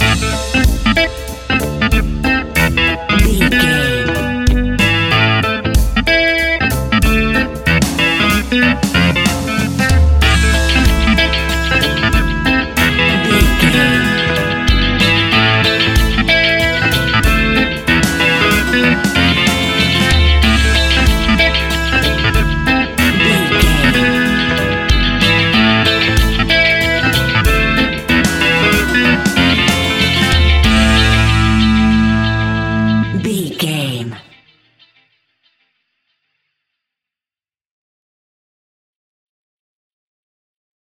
Aeolian/Minor
laid back
chilled
off beat
drums
skank guitar
hammond organ
percussion
horns